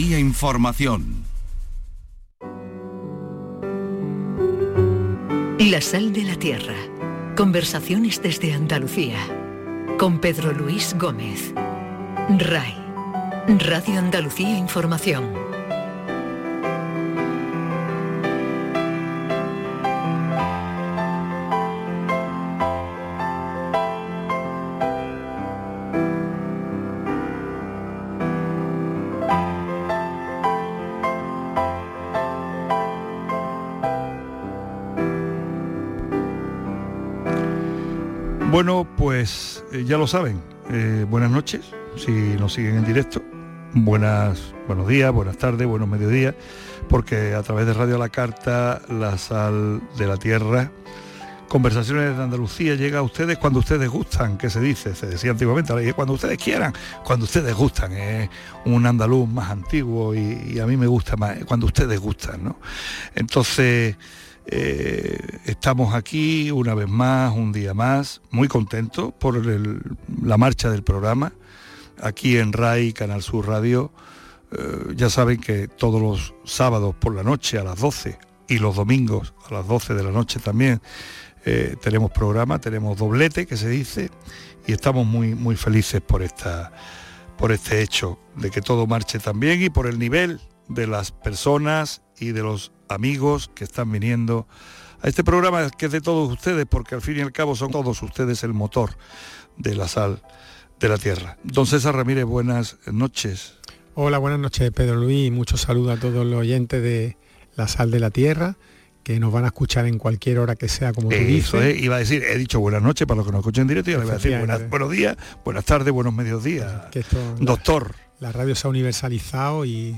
En conversaciones con hombres y mujeres nacidos en esta tierra, sur del sur, y norte necesario de tanto…